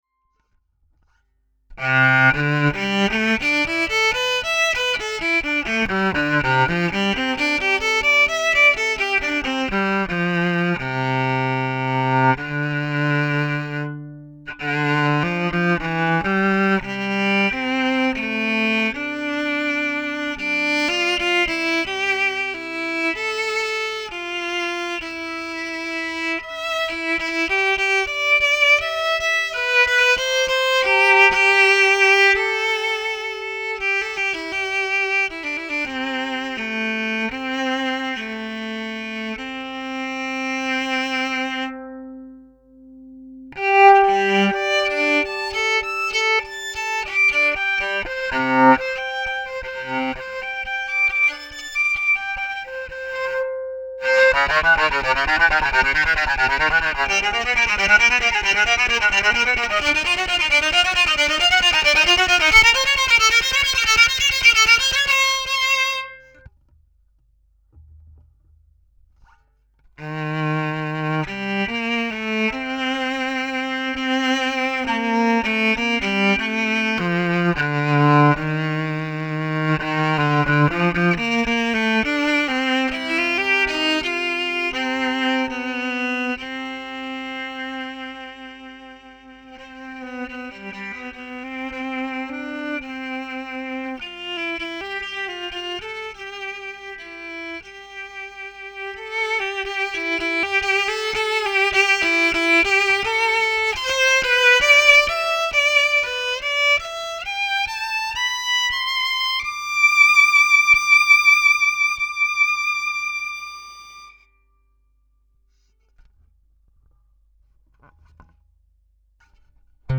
Altówka elektryczna 41.5 cm – BURSZTYN
Instrument wyposażony w instalację elektryczną zbudowaną w oparciu o przetwornik piezoelektryczny i przedwzmacniacz z regulacja głośności, zasilany baterią 9V zamontowaną w tylnej płycie instrumentu. Załączam próbkę brzmienia.
MS-Viola-Sample.mp3